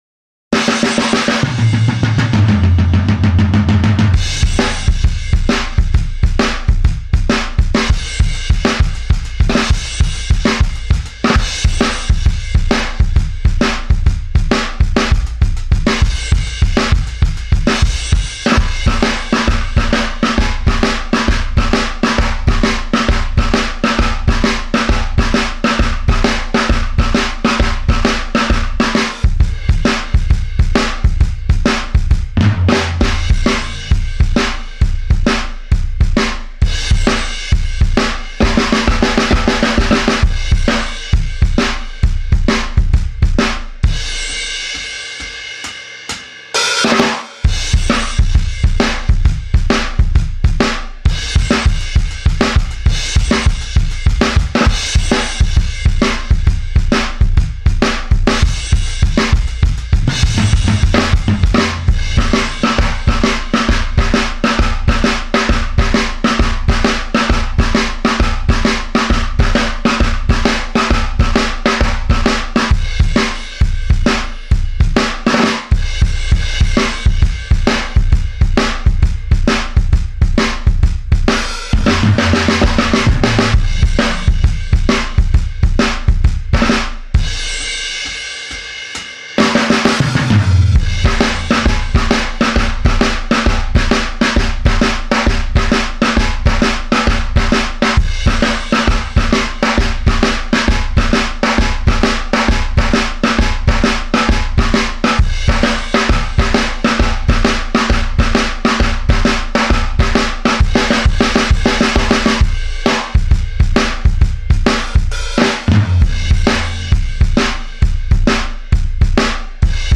Studio Kit